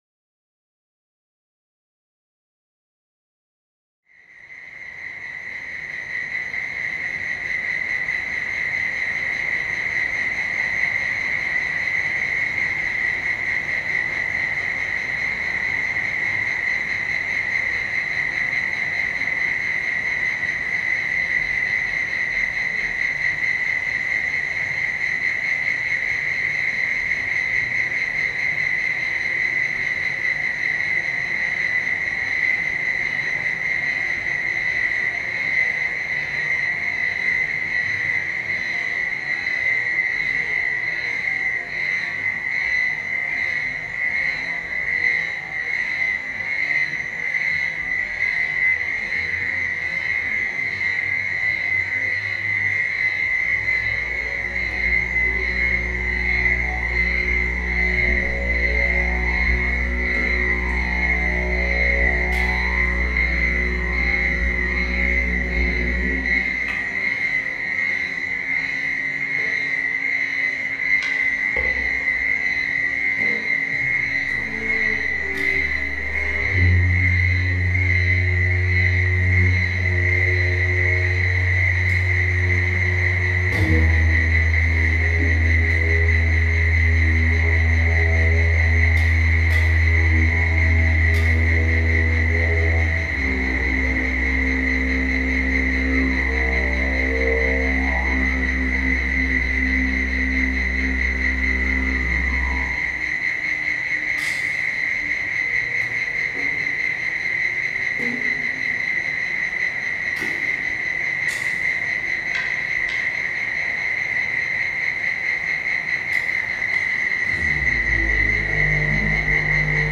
Australian cicadas reimagined